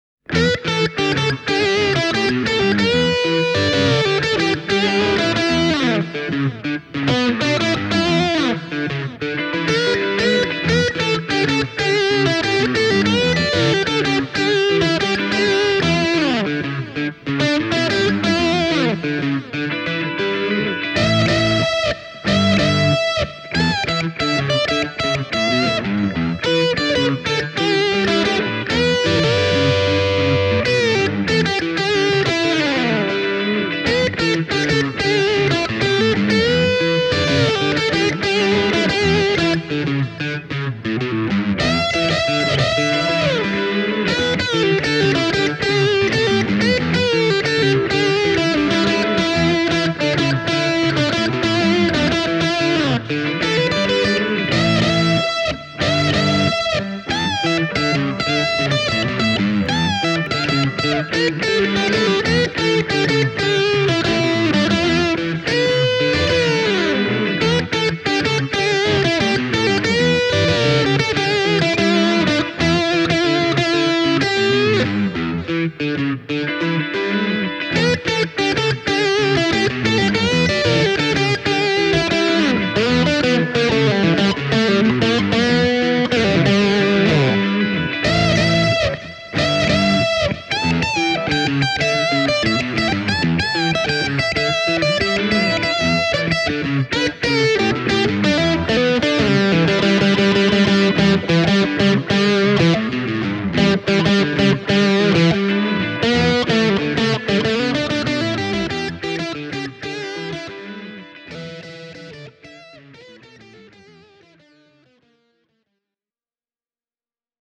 The demo track keeps the same guitars on clean rhythm duty throughout (left channel: LC-107; right channel: LS-160). The riffs and lead parts, on the other hand, change – first up is the LC-107, then the LS-130F, and the last to go is the LS-160: